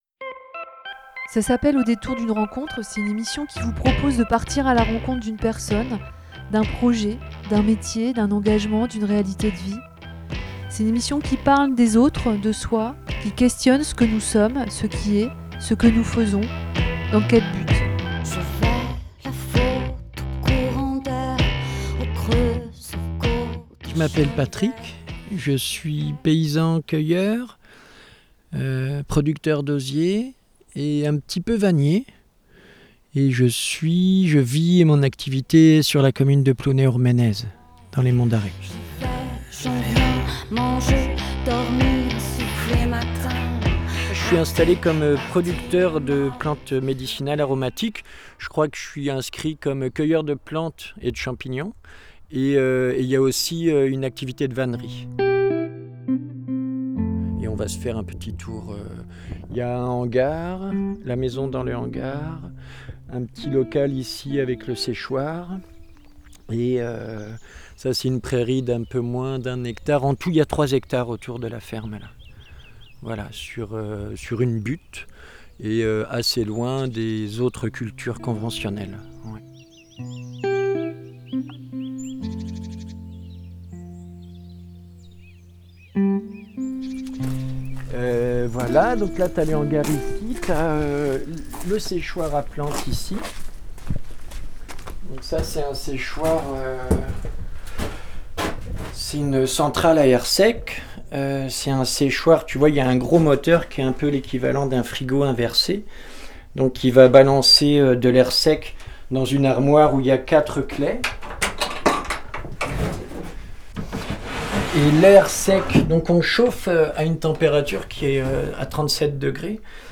Je suis allée plusieurs fois sur son lieu de travail pour découvrir, comprendre son quotidien autour de ses activités de paysan cueilleur, producteur d'osier et vannier. Ce premier épisode nous donne à entendre un lieu de vie et de travail, un cheminement personnel et professionnel, une sensibilité à la nature et au temps.